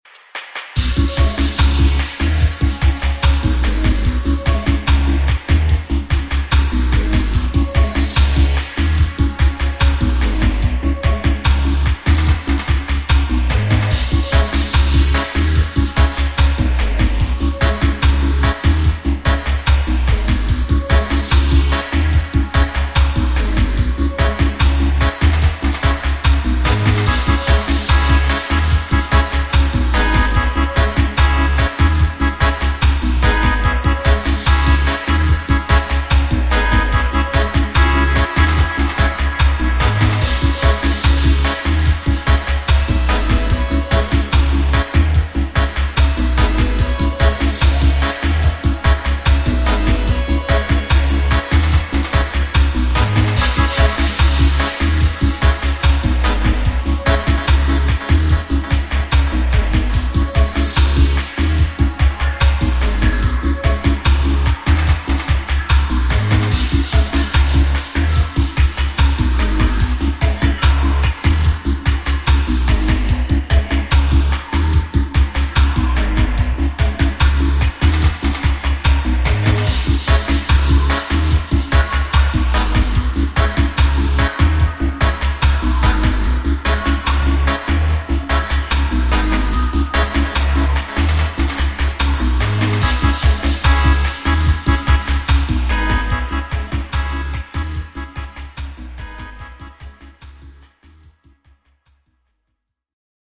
PRE VOICED